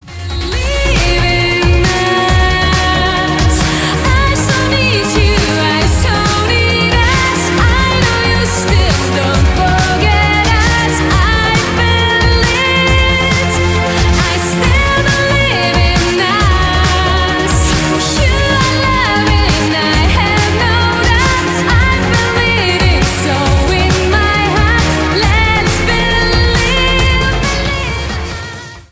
• Качество: 320, Stereo
громкие
Electronic
Стиль: trance / progressive trance